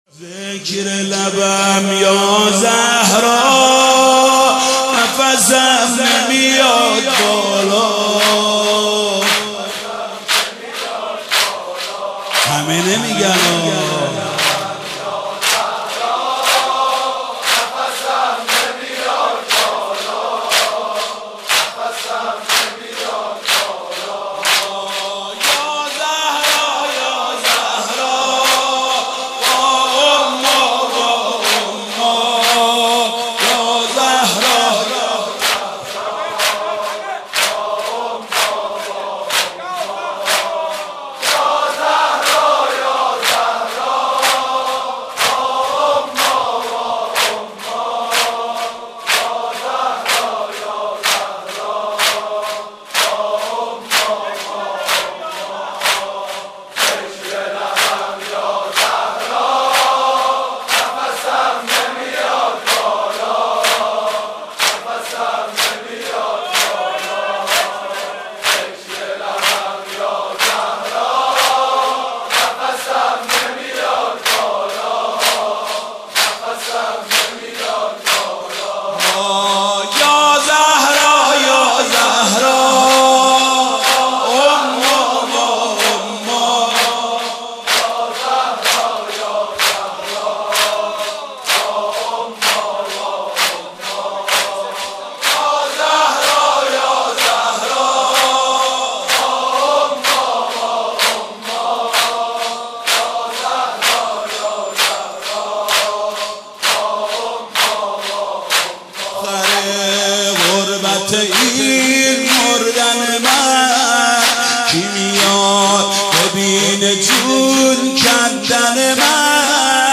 ذکر لبم یازهرا نفسم نمیاد بالا با نوای حاج محمود کریمی+ دانلود
به مناسبت شهادت نهمین اختر تابناک امامت حضرت امام جواد(ع) تقدیم شما همراهان می شود.